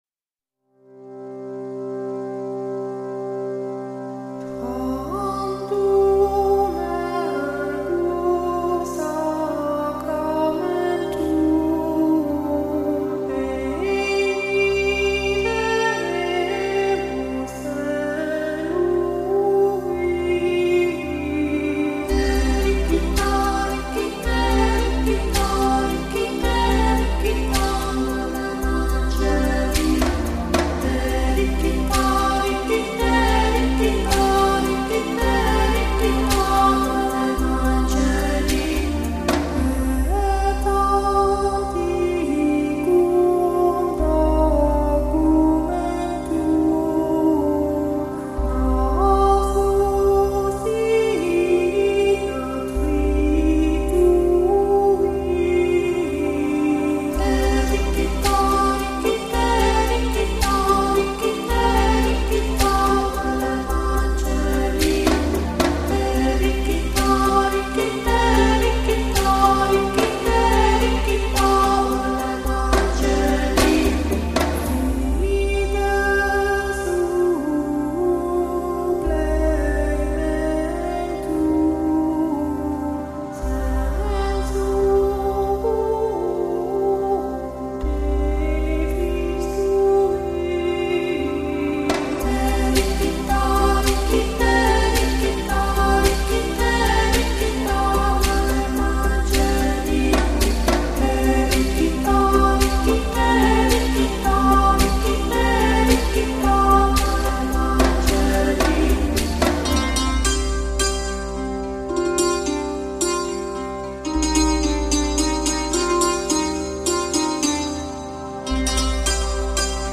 整张专辑的音乐都偏向凯尔特风格
包括有类似于唱诗班和声、有钢琴独奏、还有竖琴音乐等。使用的乐器包括了键盘、竖琴、长笛、钢琴、风笛等。